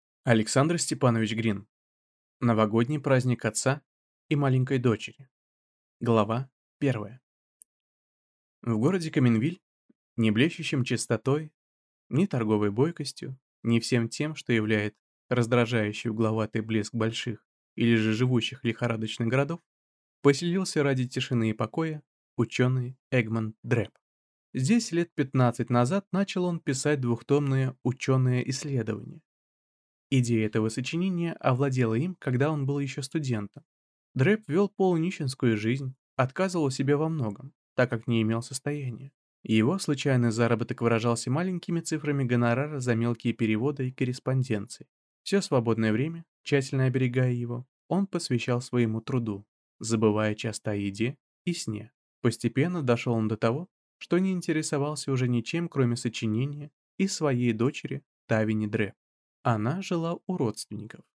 Аудиокнига Новогодний праздник отца и маленькой дочери | Библиотека аудиокниг